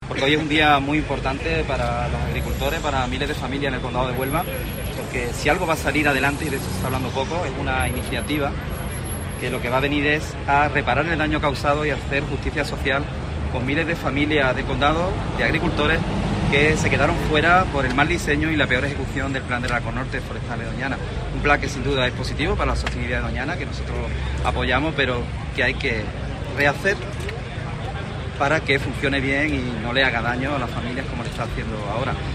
En declaraciones a los periodistas en la puerta del Parlamento